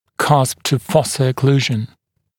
[kʌsp-tə-‘fɔsə ə’kluːʒn][касп-ту-‘фосэ э’клу:жн]фиссурно-бугорковая окклюзия